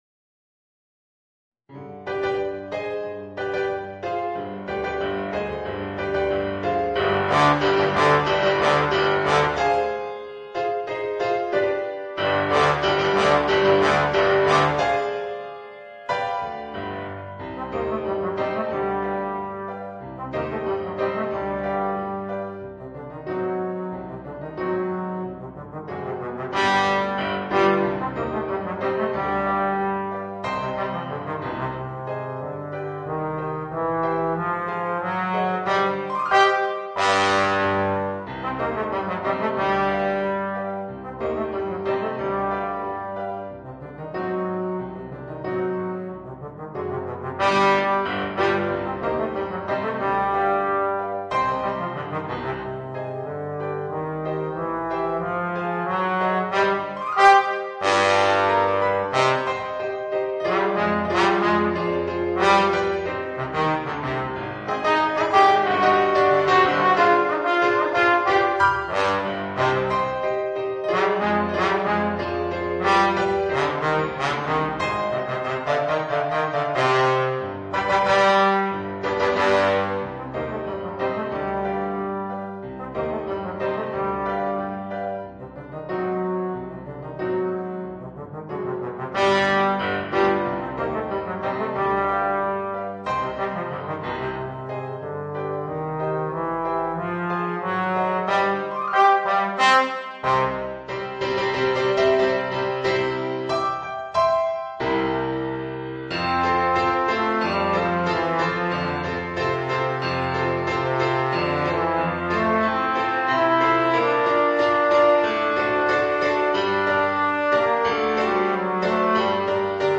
Voicing: Trombone w/ Audio